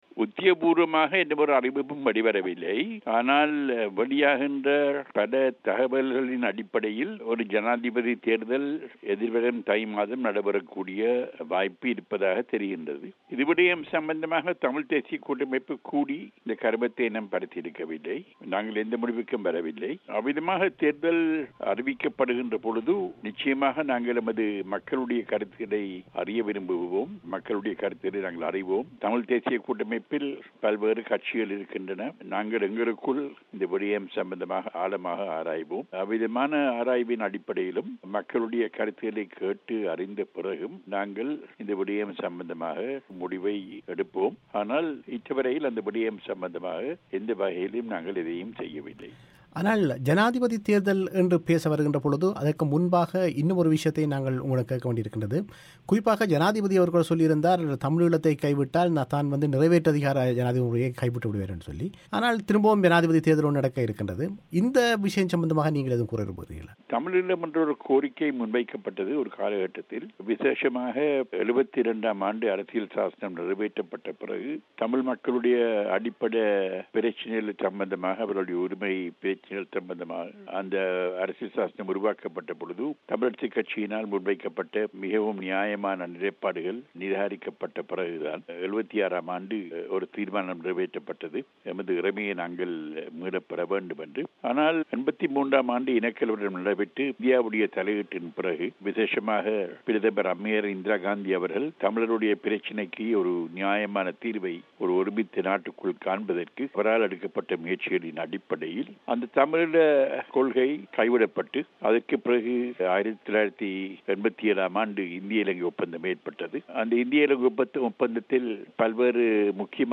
இந்த விடயங்கள் குறித்து அவர் பிபிசி தமிழோசைக்கு வழங்கிய செவ்வியை நேயர்கள் இங்கு கேட்கலாம்.